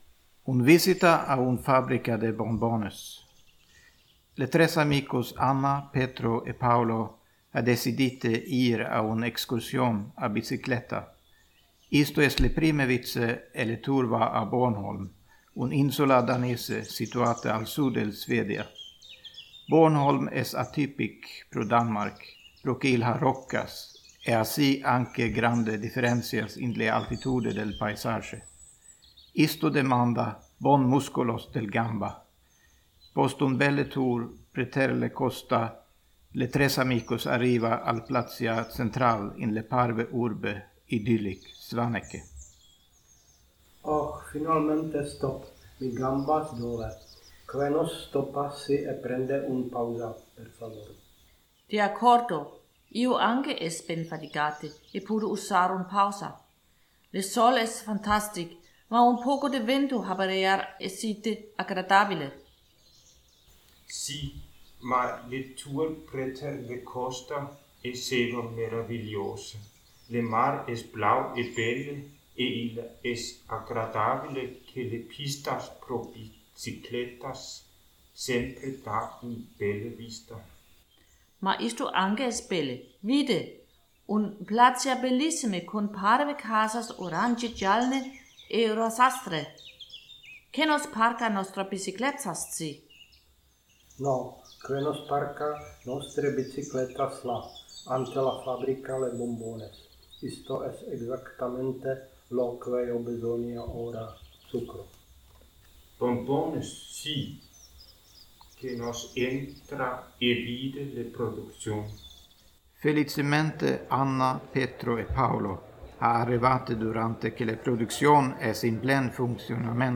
Description: "Viages con interlingua" es un serie de conversationes quotidian con utile phrases e parolas.